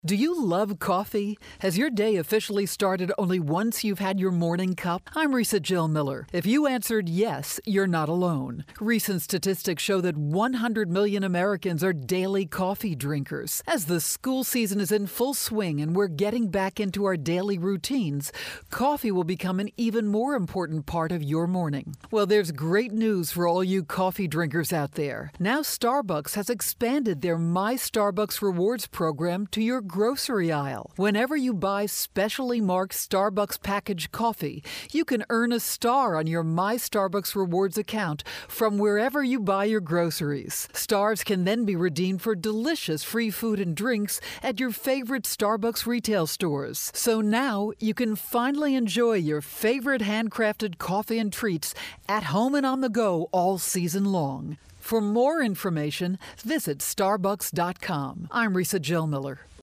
August 28, 2013Posted in: Audio News Release